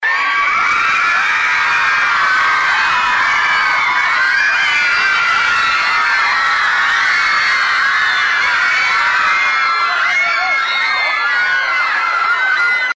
Ovacije za glumca
Ovo je delić holivudske atmosfere i ovacija koje su glumcu Buraku Ozčivitu priredili brojni fanovi, dok je crvenim tepihom, uz bliceve aparata, ulazio u dvoranu Kulturnog centra.